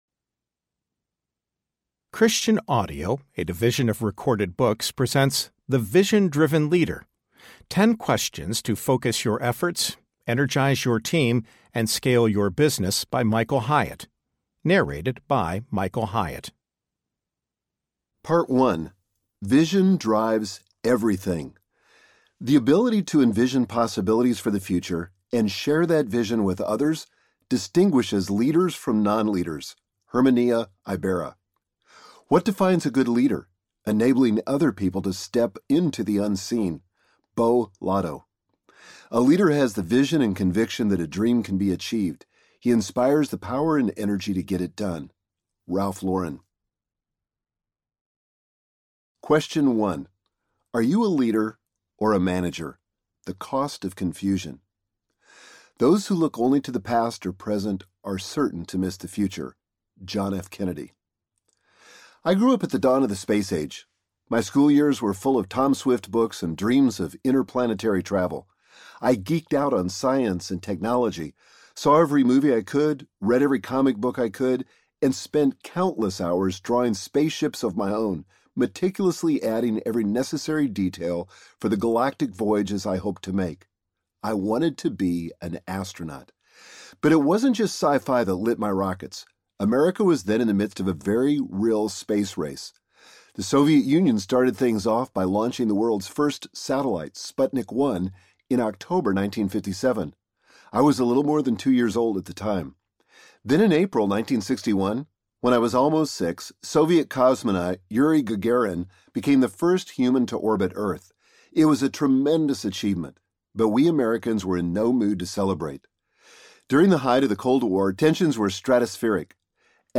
The Vision-Driven Leader Audiobook